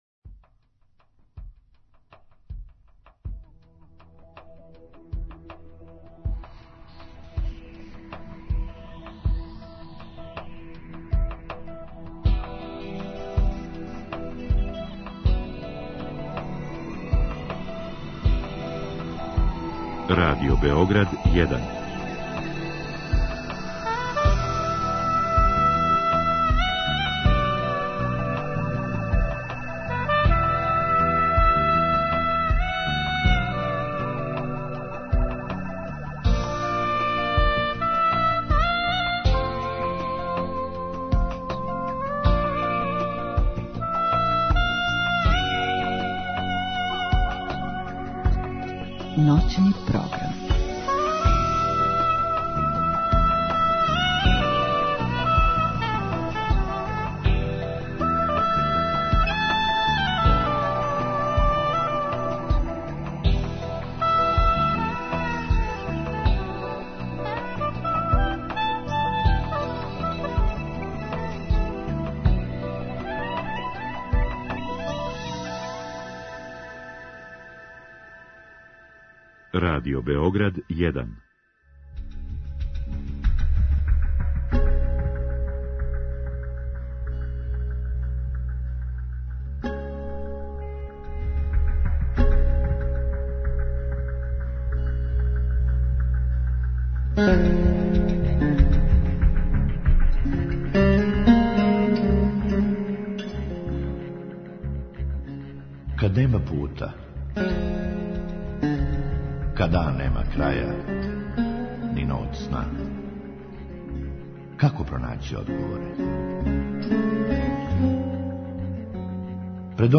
У другом сату гошћи постављамо питања која су стигла путем наше Инстаграм странице.